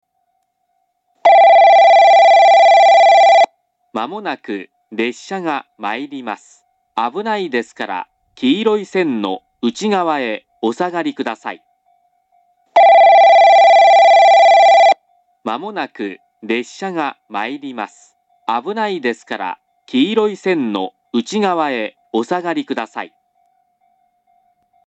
遠隔の詳細放送と従来からあると思われる簡易放送の２種類があります。
この駅の放送はどちらのホームに入線する場合でも上り列車は１番線のスピーカー、下り列車は２番線のスピーカーから放送が流れます。
１番線上り接近放送